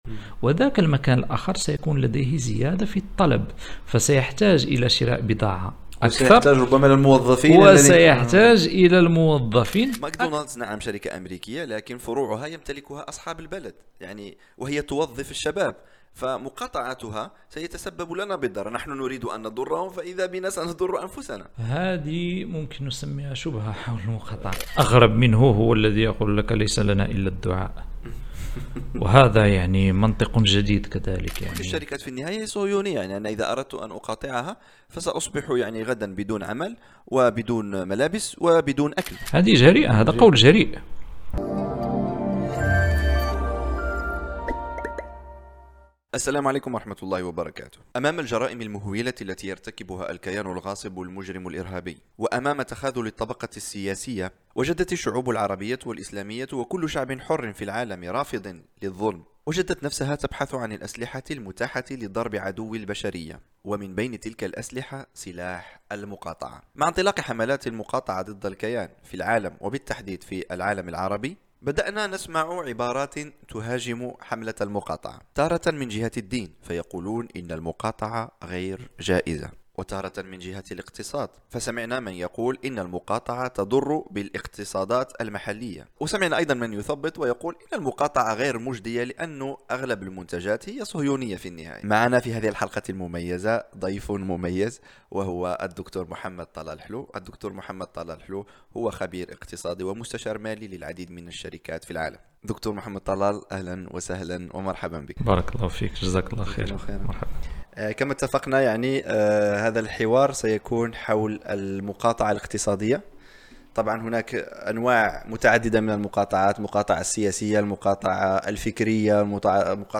حوارات السبيل | هل المقاطعة تؤثر؟